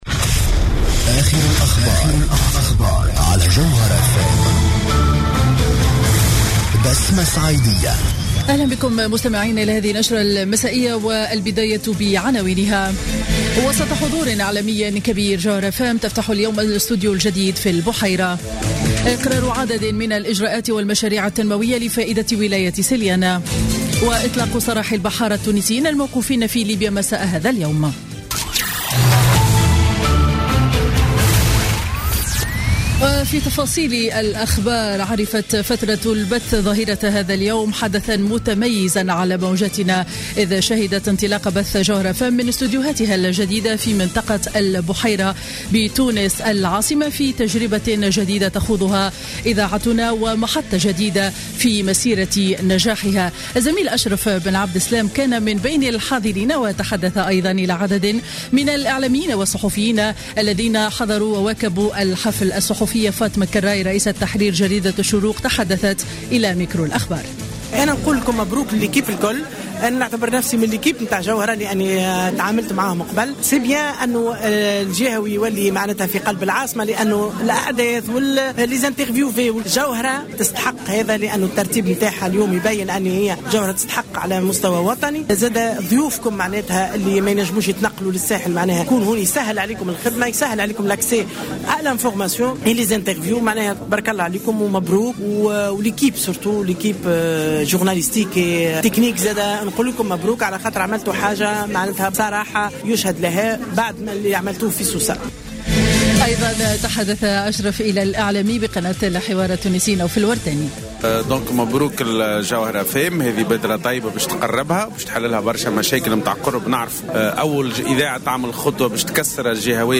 Journal Info 19:00 du mardi 29 Décembre 2015